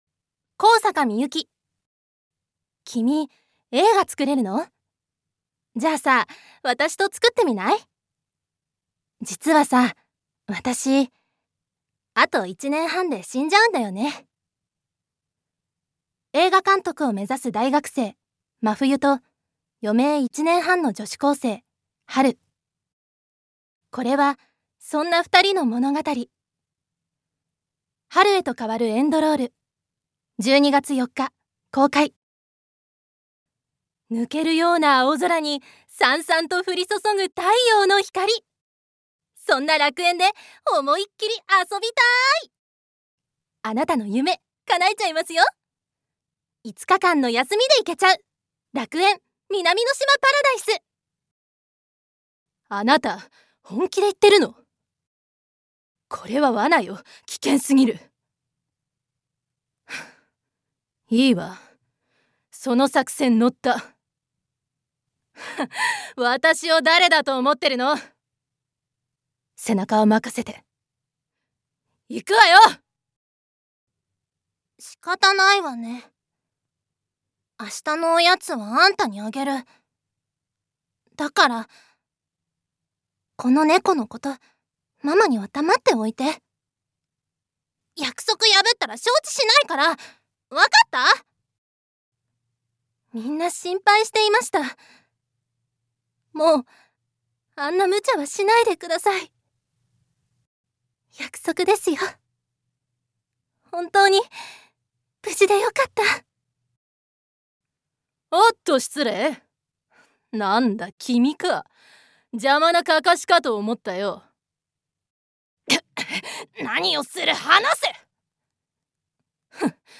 所属：サロン・ド・アクトゥリス出身：愛知県生年月日：11月27日血液型：O型身長：160㎝特技：お菓子作り・小説を書くこと趣味：ピアス集め・散歩・ゲーム資格：色彩検定3級 フードコーディネーター3級 サービス接遇検定3級One shot Voice（サンプルボイスの視聴）